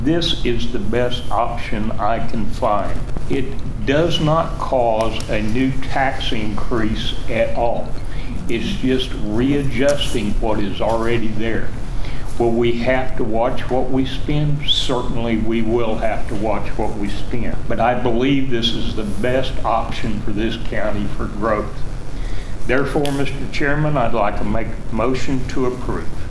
here is what District Three Commissioner Charlie Cartwright had to say on the topic.